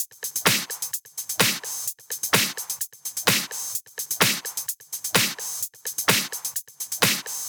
VFH1 128BPM Moonpatrol Kit 5.wav